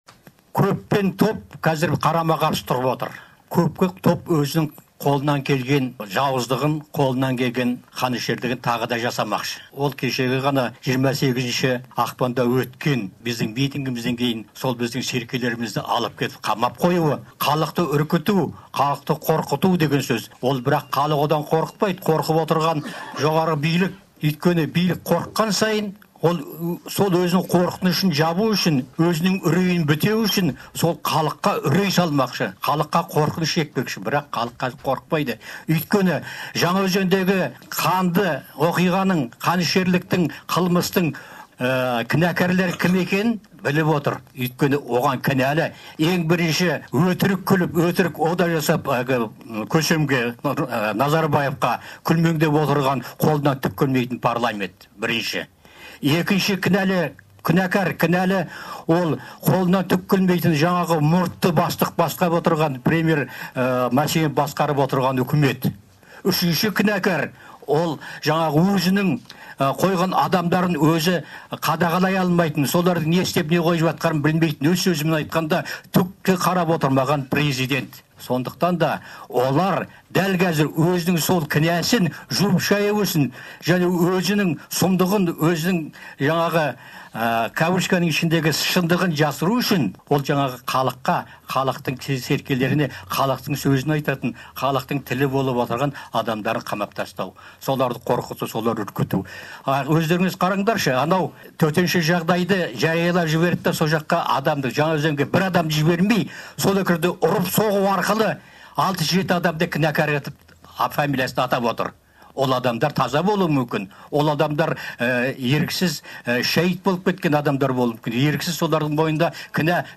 Алматыда бір топ оппозиция өкілдері мен қоғамдық белсенділер баспасөз мәслихатын ұйымдастырып